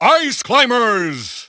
The announcer saying Ice Climbers' names in English releases of Super Smash Bros. Brawl.
Category:Ice Climbers (SSBB) Category:Announcer calls (SSBB) You cannot overwrite this file.
Ice_Climbers_English_Announcer_SSBB.wav